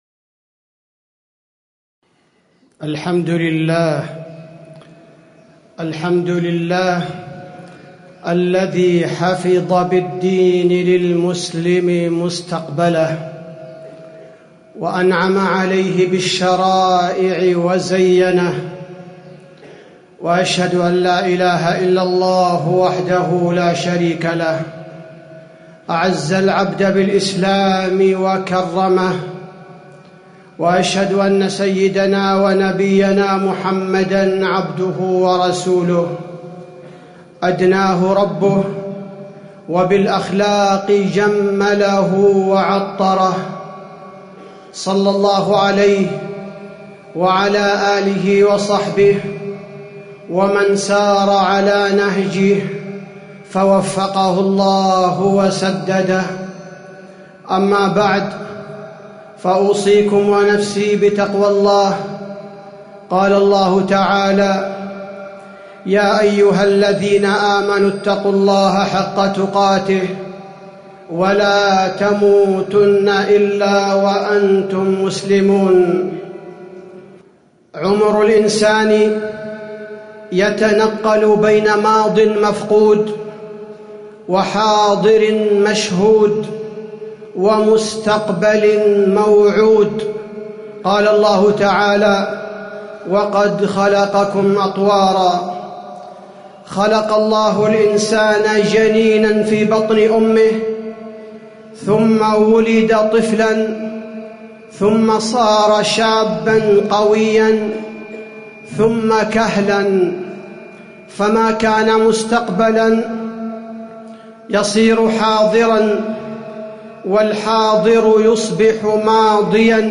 تاريخ النشر ١١ جمادى الآخرة ١٤٣٨ هـ المكان: المسجد النبوي الشيخ: فضيلة الشيخ عبدالباري الثبيتي فضيلة الشيخ عبدالباري الثبيتي استقرائي المستقبل يحمي الأمم من الأزمات